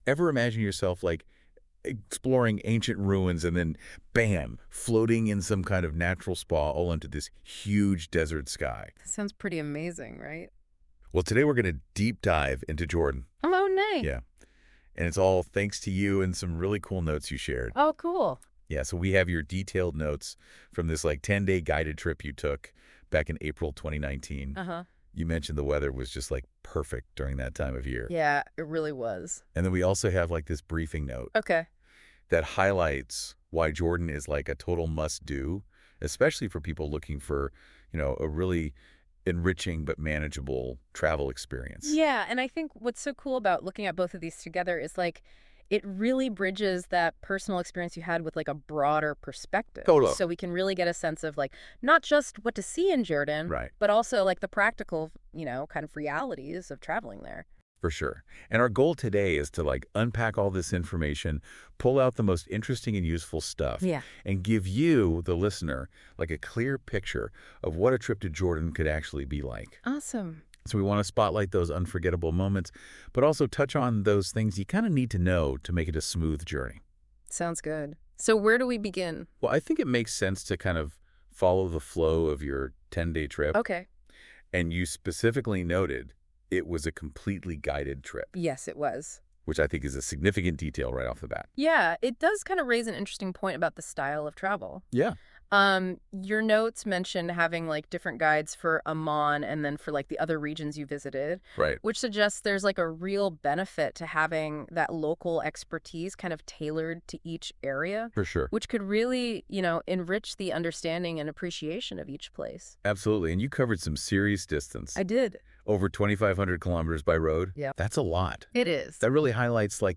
Petra in Jordan PodCast of this blog in conversation form available for easy listening ( Link from icon above ) A sense of Jordan Just to see the temples in Petra, alone, would be reason enough to visit Jordan.